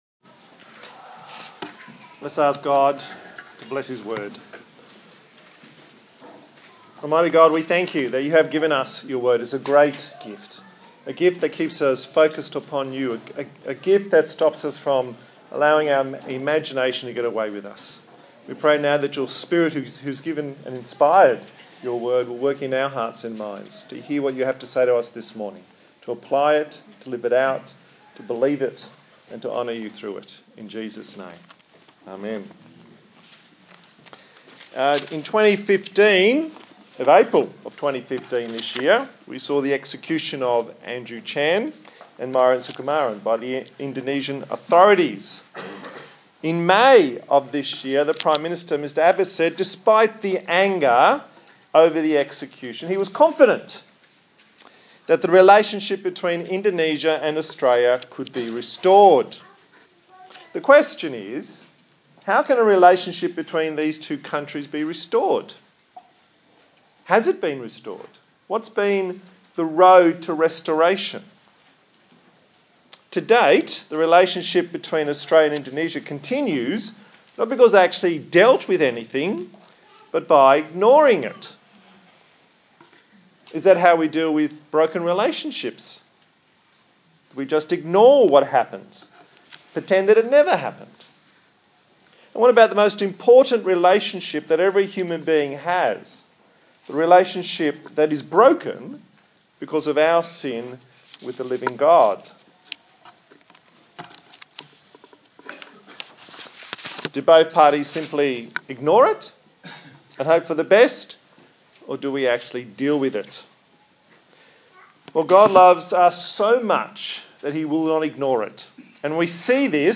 30/08/2015 The 3 Gospel R’s Preacher
Service Type: Sunday Morning